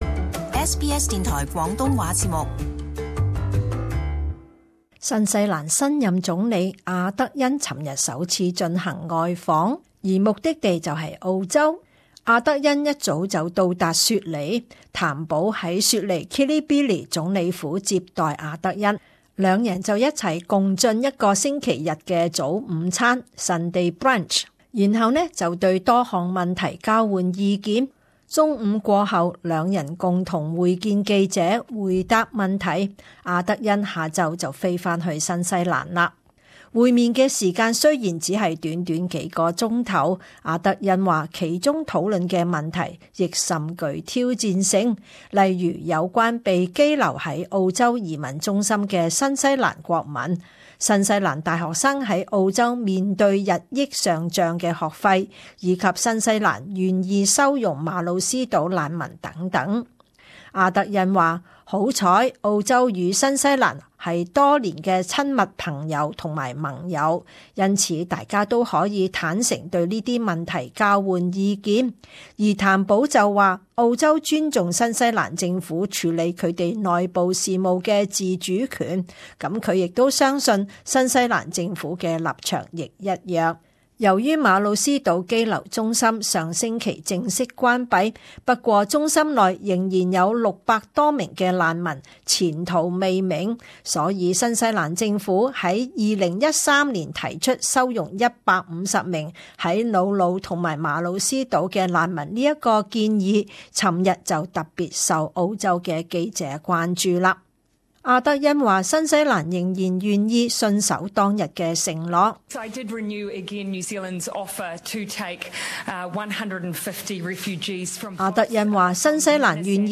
【時事報導】澳州為何拒絕新西蘭協助收容難民？